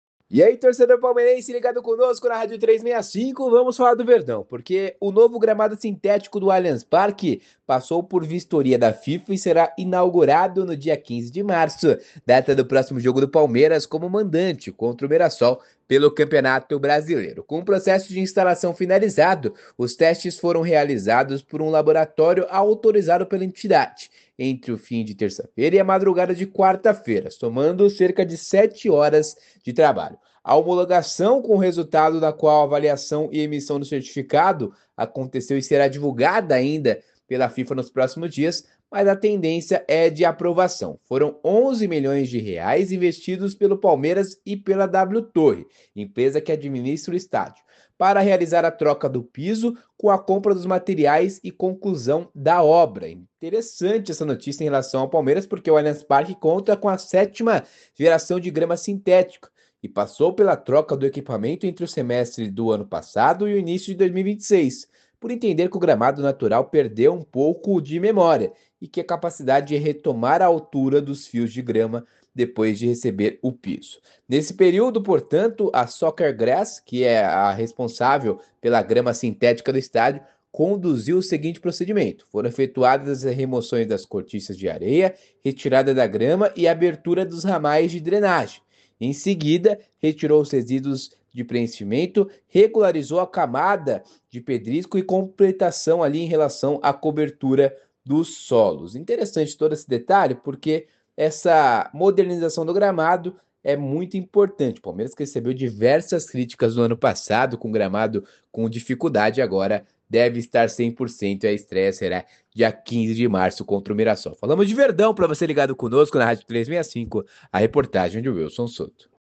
Boletin em áudio